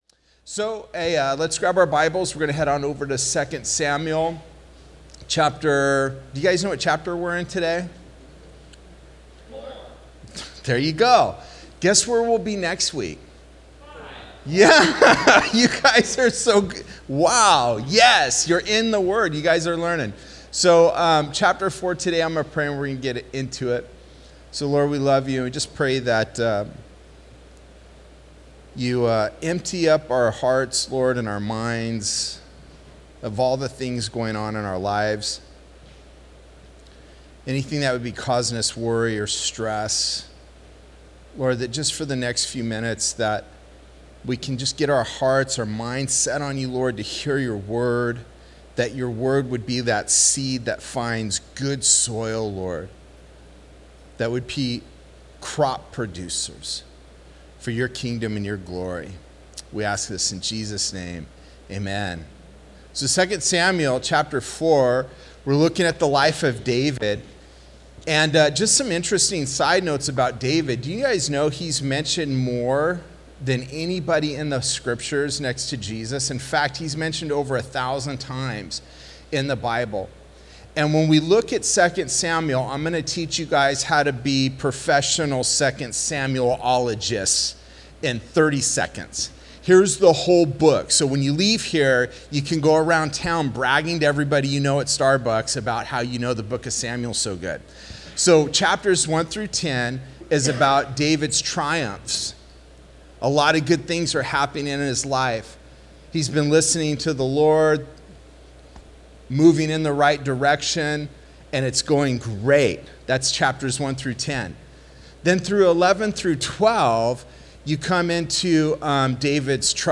Sermons Archive - Page 15 of 47 - Ark Bible Church